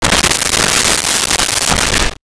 Toilet Paper 1
toilet-paper-1.wav